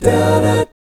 1-ABMI7   -L.wav